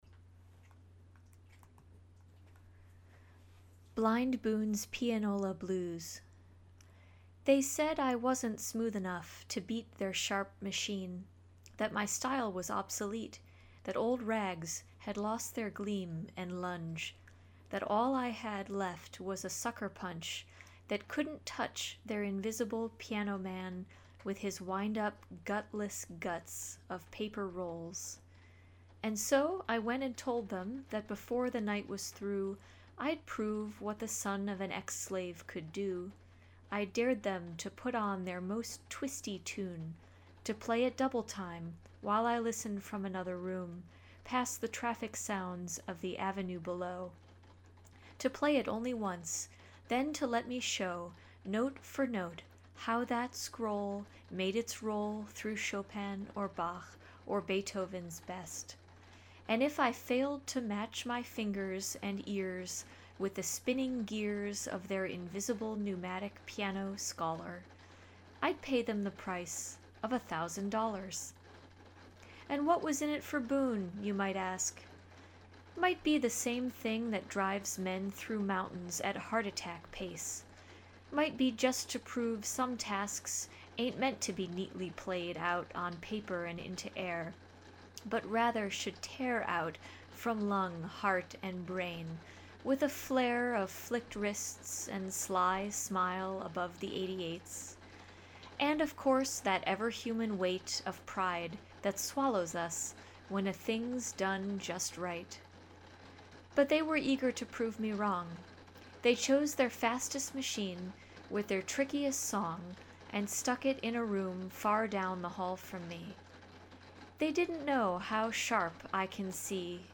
my reading (with a rainstorm in the background).